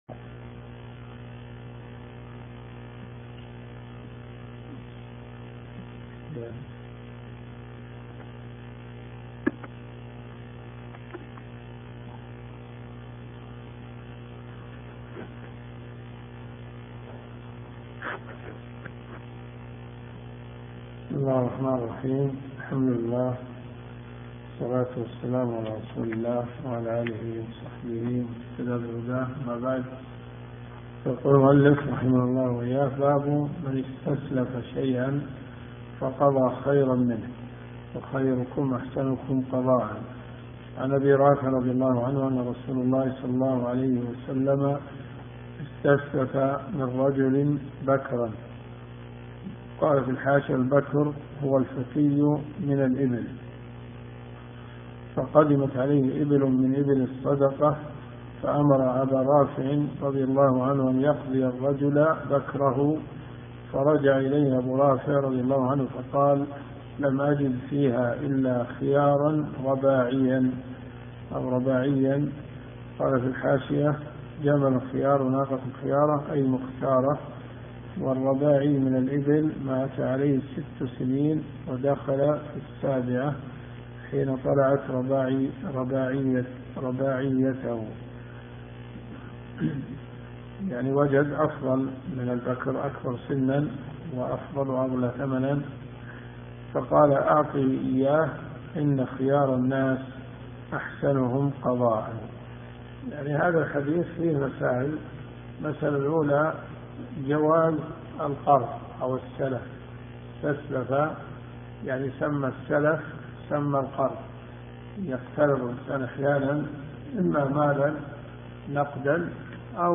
دروس صوتيه
اذا الصوت ضعيف استخدم سماعة الاذن